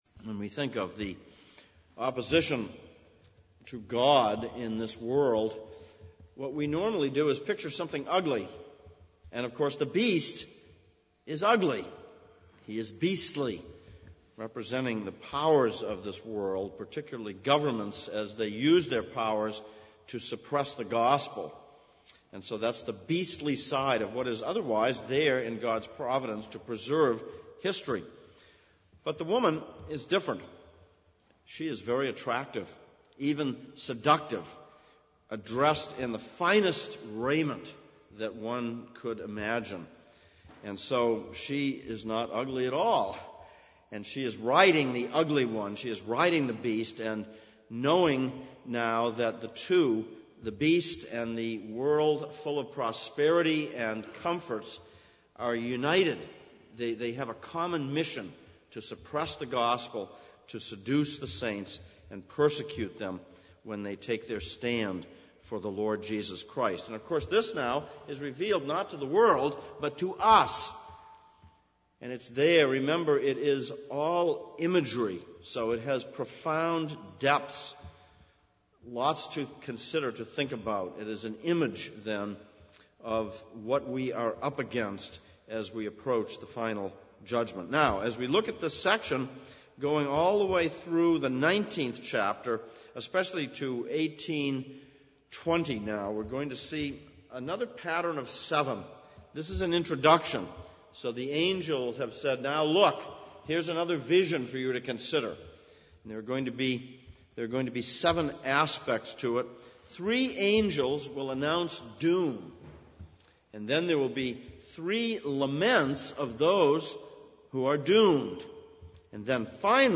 Exposition of Revelation Passage: Revelation 17:1-6, Jeremiah 51:1-13 Service Type: Sunday Evening « 27.